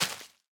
Minecraft Version Minecraft Version snapshot Latest Release | Latest Snapshot snapshot / assets / minecraft / sounds / block / big_dripleaf / break2.ogg Compare With Compare With Latest Release | Latest Snapshot